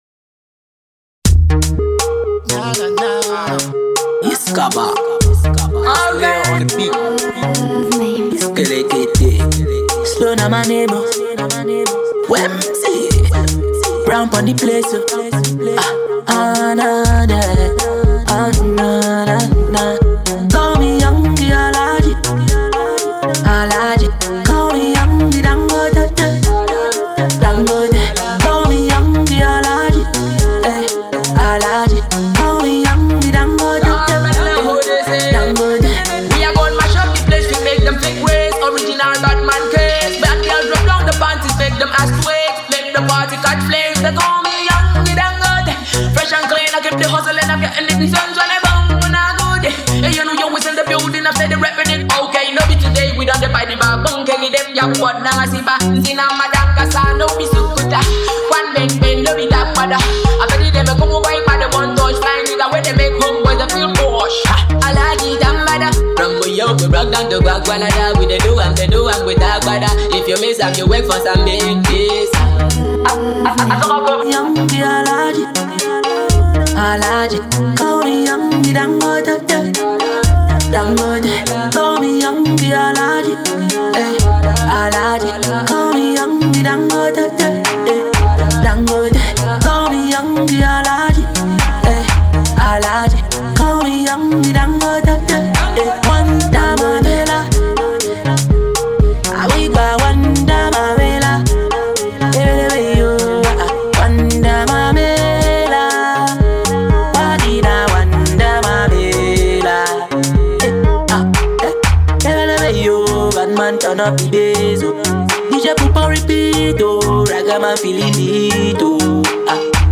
soft reggae
this be one groovy vibe